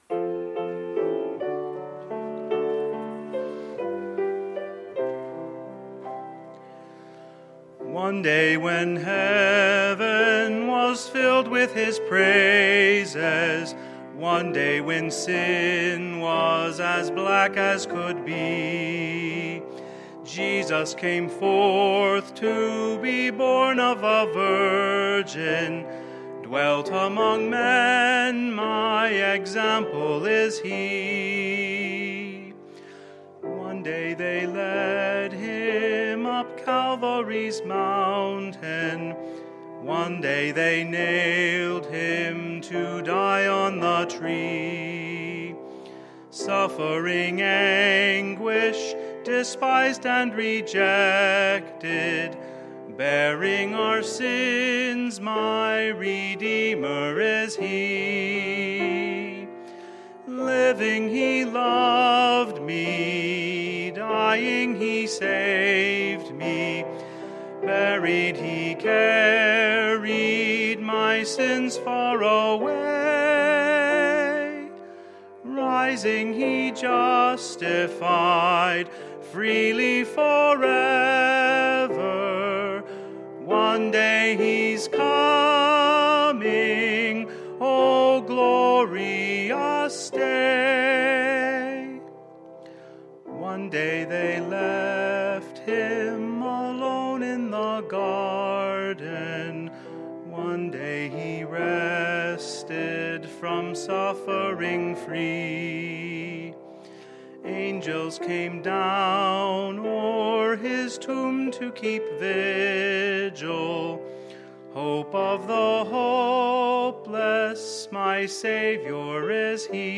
Sunday, April 16, 2017 – Sunday Morning Service